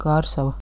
pronunciation
Lv-Kārsava.ogg.mp3